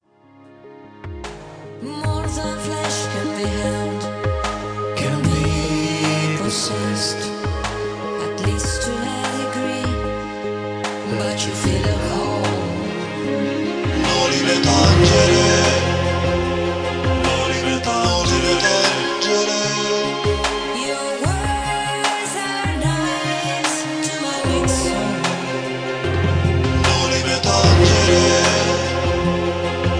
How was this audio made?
Full remastered song on 2012.